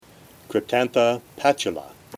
Pronunciation/Pronunciación:
Cryp-tán-tha  pà-tu-la